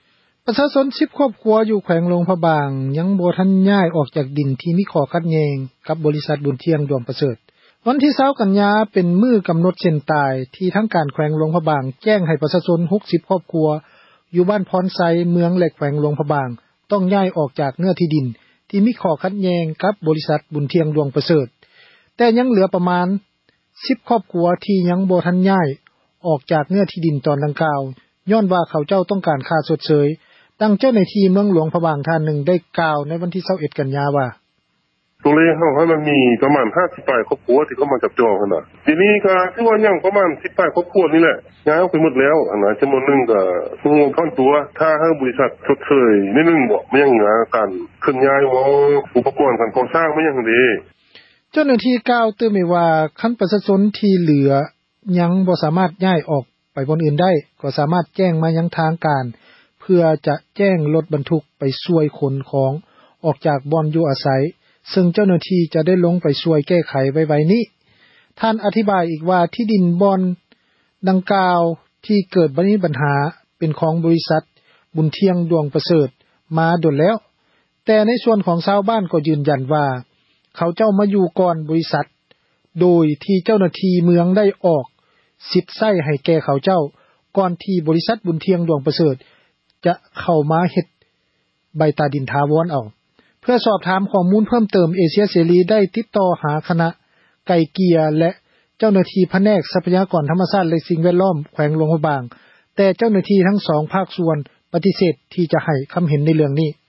ແຕ່ຍັງເຫລືອປະມານ 10 ຄອບຄົວ ທີ່ຍັງບໍ່ທັນ ຍ້າຍອອກຈາກ ເນື້ອທີ່ດິນຕອນດັ່ງກ່າວ ຍ້ອນວ່າ ເຂົາເຈົ້າຕ້ອງການ ຄ່າຊົດເຊີຍ, ດັ່ງ ເຈົ້າໜ້າທີ່ ເມືອງຫລວງພຣະບາງ ທ່ານນຶ່ງ ກ່າວໃນວັນທີ 21 ກັນຍາ ວ່າ: